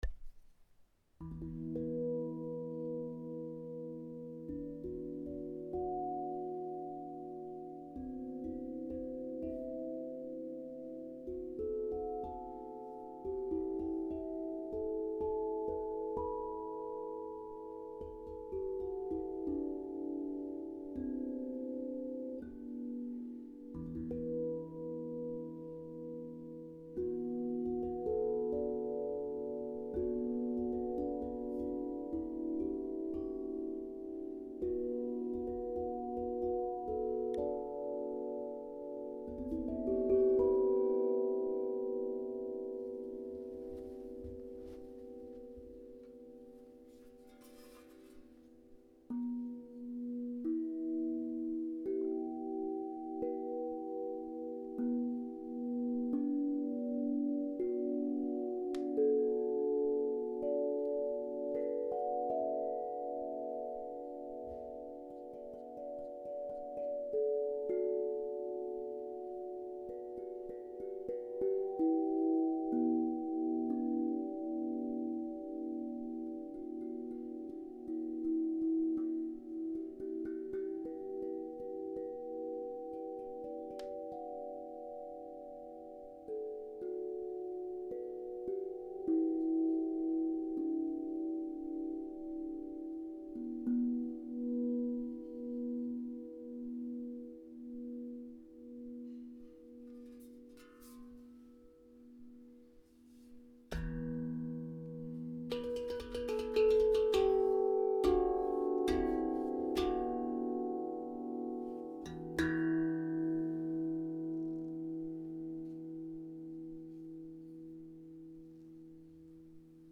Tongue drum Magic Mi Kurd et B Amara - Osb Drum
Le Tongue Drum Magic en Mi Kurd et Si Amara: L’Harmonie Parfaite entre un côté mineur et un autre polyvalent.